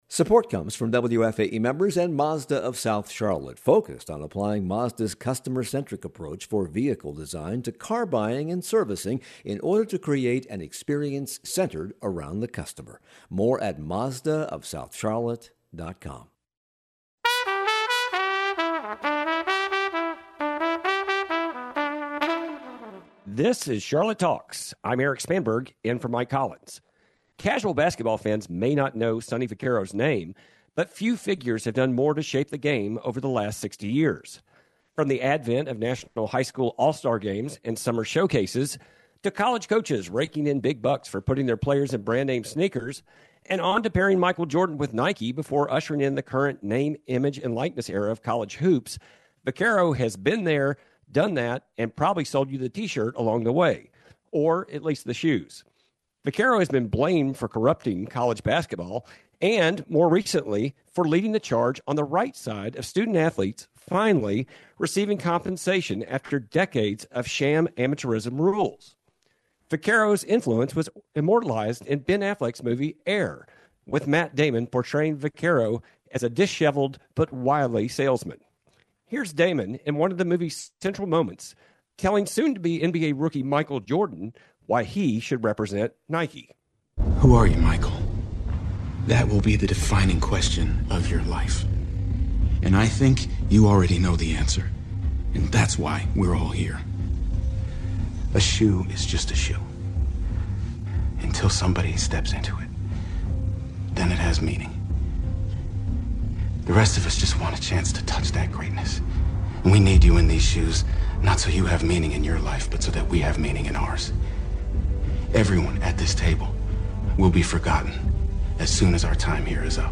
A conversation with the man who took Michael Jordan and Kobe Bryant’s careers to legendary heights. From the biggest deals in the history of sports marketing to a campaign against the NCAA, we sit down with Sonny Vaccaro and his co-author, Armen Keteyian, to discuss his new book "Legends and Soles."